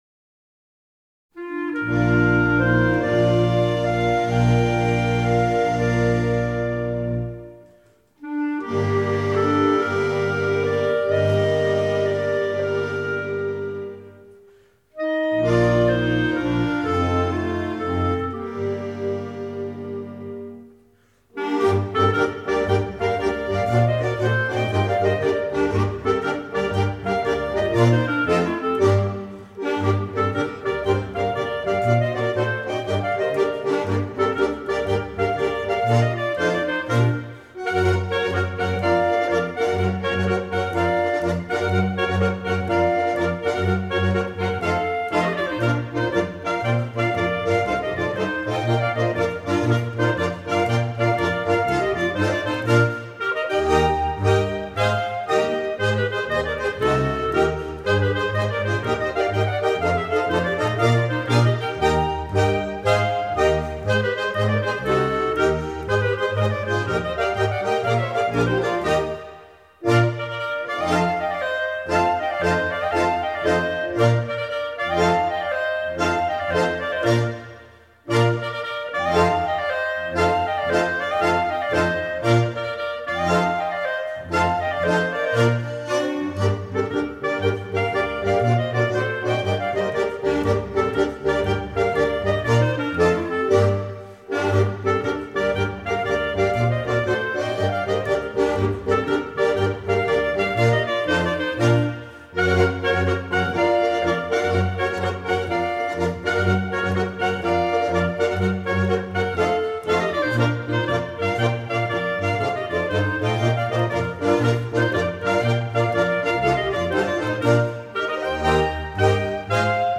Traditional music from the Chablais Region
This recording highlights music from an area which, due to its unique geographic location, is of particular interest both musically and culturally. It also includes rare takes of carillon music.